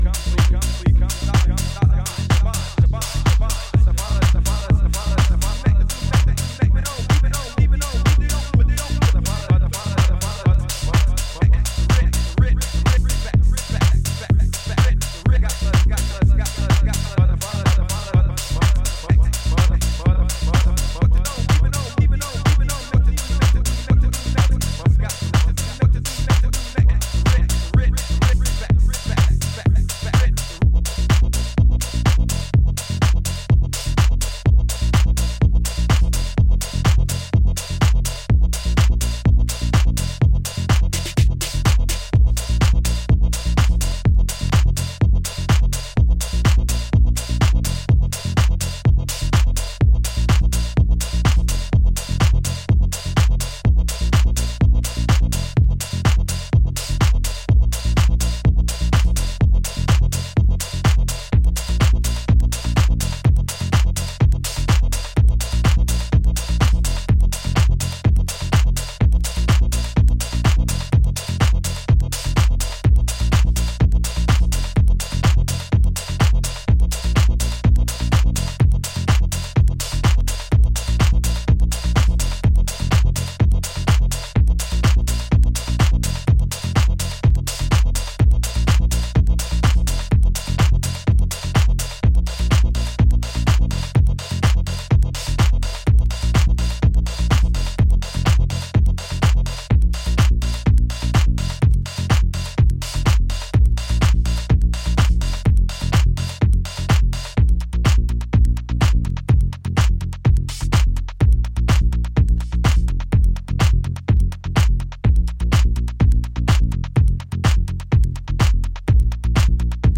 Euphoric piano stabs live beside Chicago-esque key solos